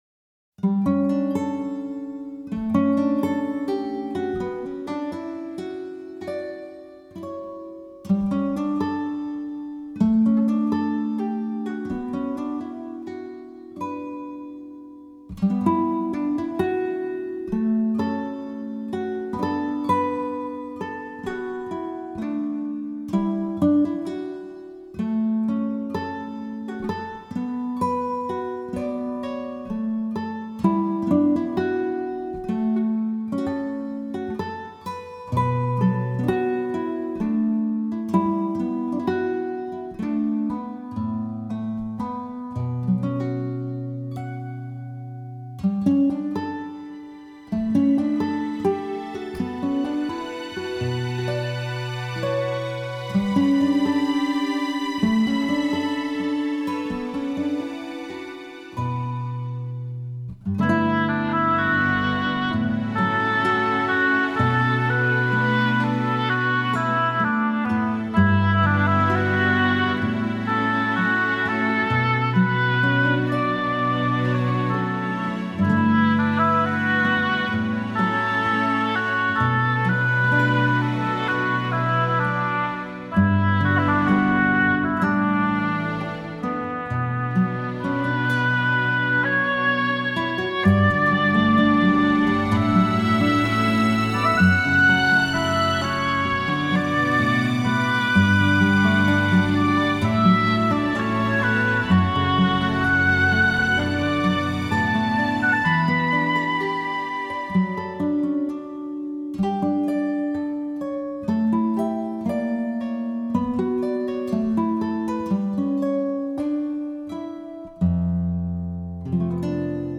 流派：Soundtrack
语言：纯音乐
擅长新世纪音乐风格创作，曲风纤细富于透明感，不仅在New Age音乐大受欢迎的日本得到好评，而且在韩国赢得了很高的人气。